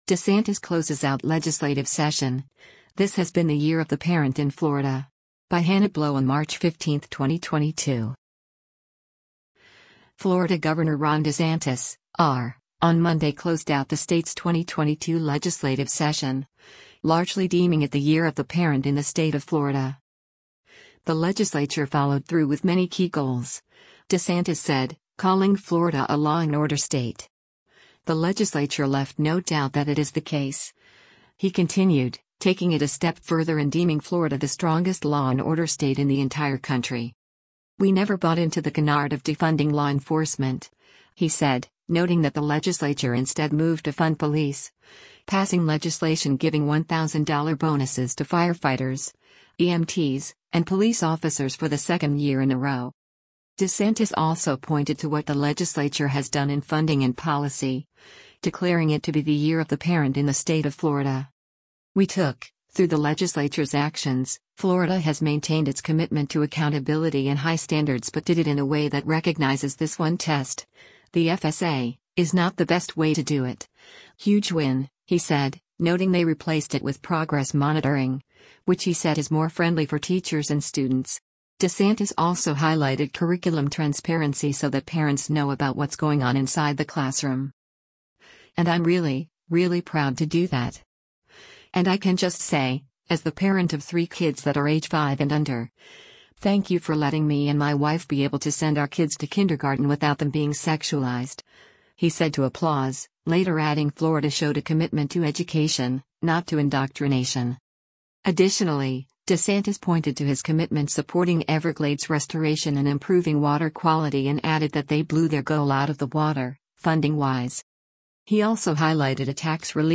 “And I’m really, really proud to do that. And I can just say, as the parent of three kids that are age five and under, thank you for letting me and my wife be able to send our kids to kindergarten without them being sexualized,” he said to applause, later adding Florida showed a commitment to “education, not to indoctrination.”